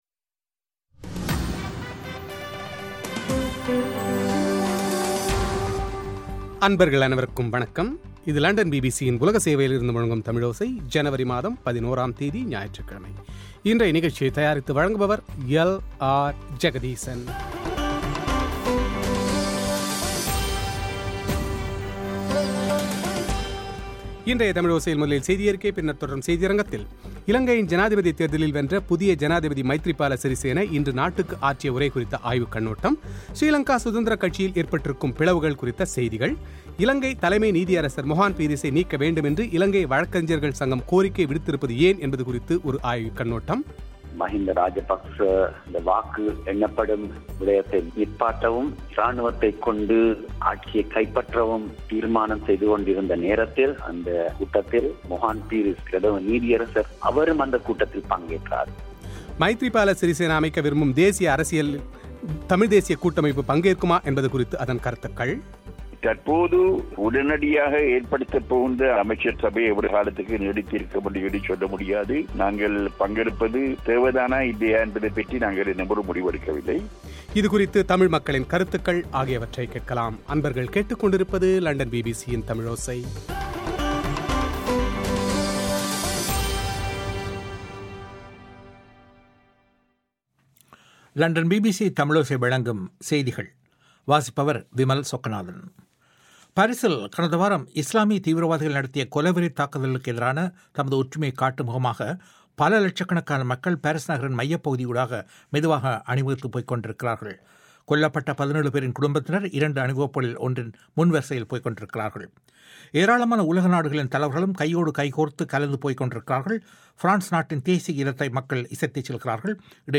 செவ்வி